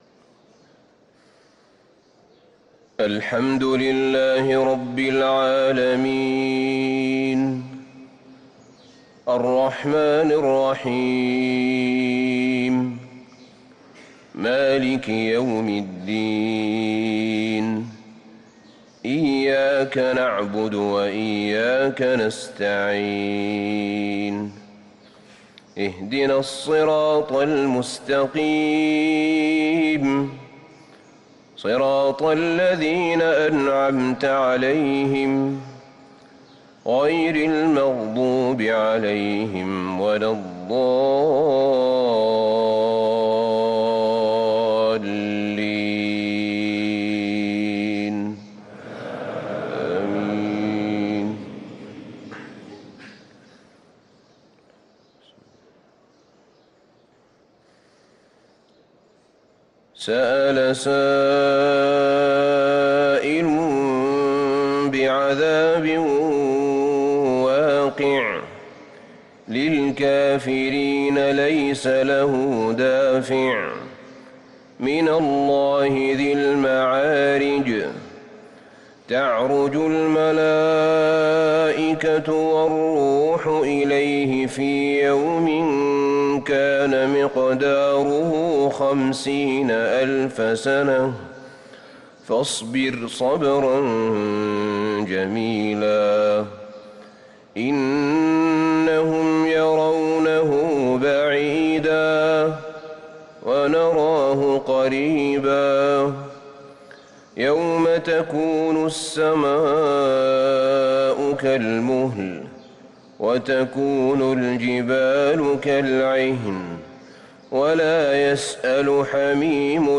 صلاة الفجر للقارئ أحمد بن طالب حميد 9 جمادي الأول 1444 هـ
تِلَاوَات الْحَرَمَيْن .